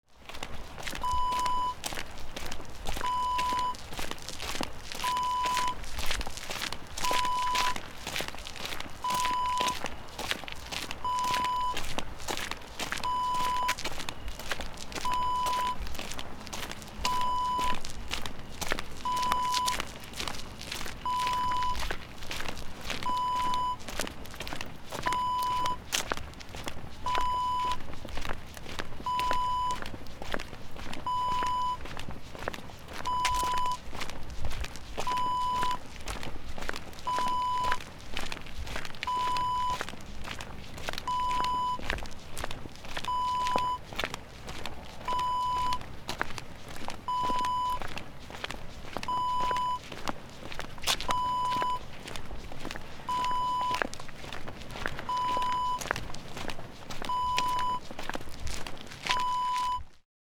Footsteps on Paved Walkway Wav Sound Effect
Description: Walking on paved walkway
Properties: 48.000 kHz 16-bit Stereo
A beep sound is embedded in the audio preview file but it is not present in the high resolution downloadable wav file.
footsteps-on-walkway-preview-1.mp3